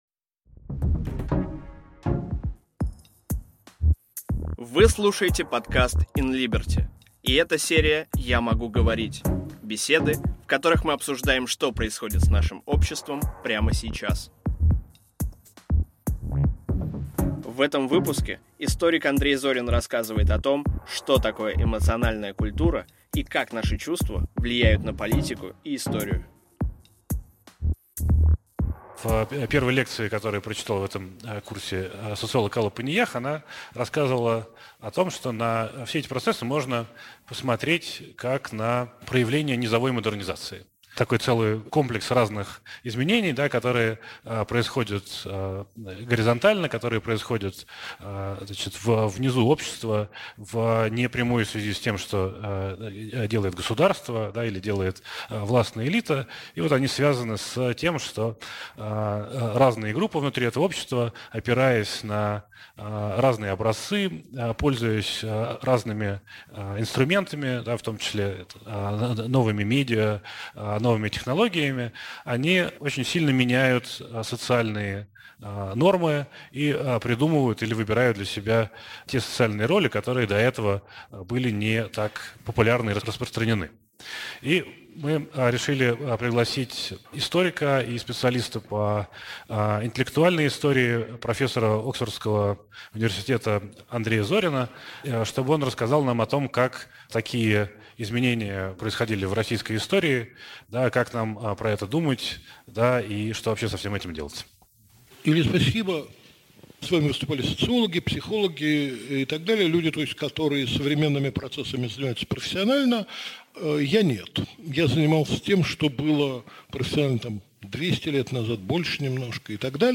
Aудиокнига Как трансформируется эмоциональная культура? Автор Андрей Зорин.